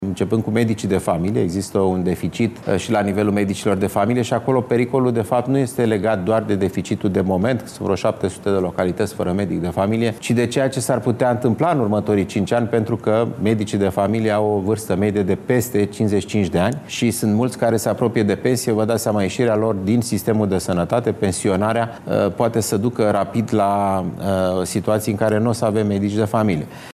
Ministrul Sănătății, la postul B1TV: